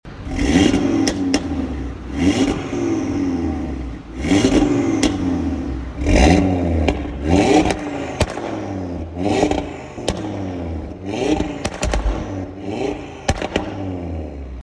Listen to this 5-Cylinder Brute!
• RS Sports Exhaust System
• 2.5TFSI 5-Cylinder Engine: Aluminum Block Model
audi-rs3-8v-sportback-panther-black-mrc-stage-2-zmy-Revs.mp3